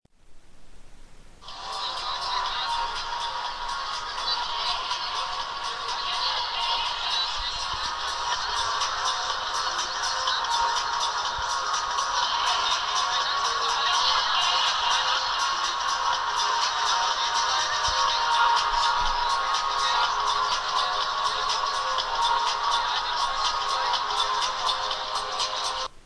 Ja ist auf jeden Fall ein Electro Track.